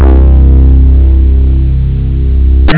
labrat mooglike.wav